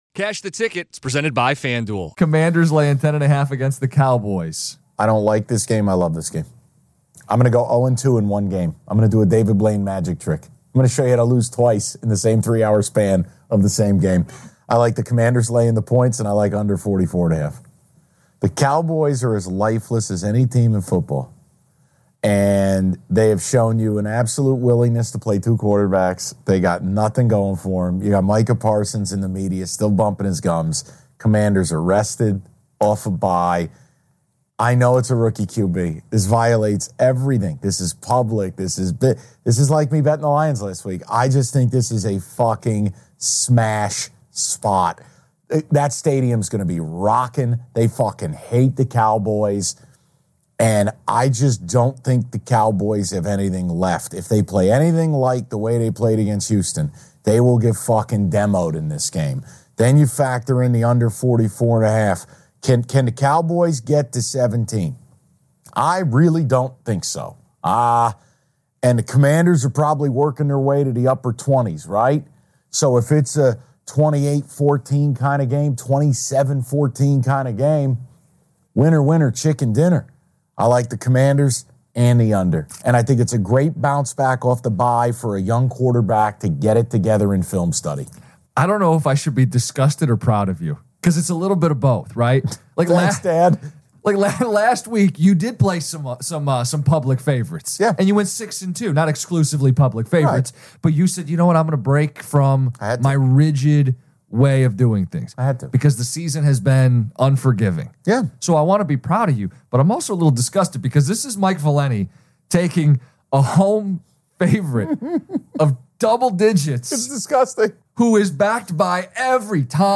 Comedy Sports Audacy Sports Junkies